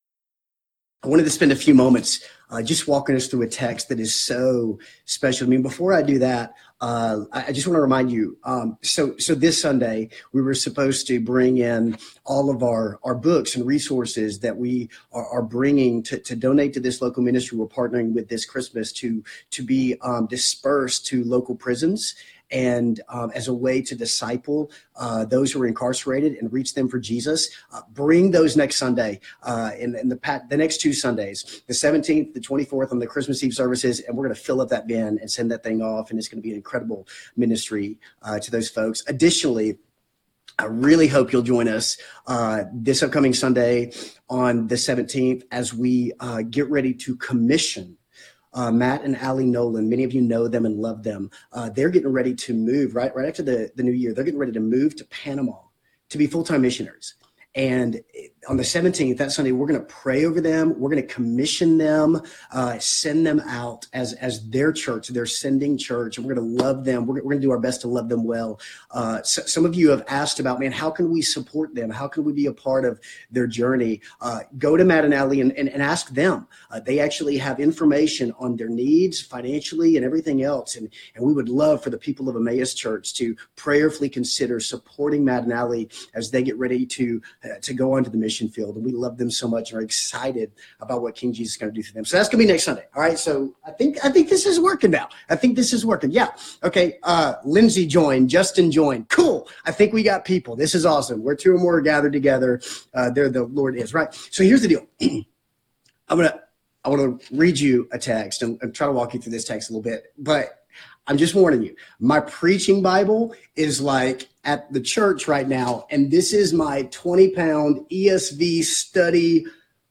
Facebook Live specail sermon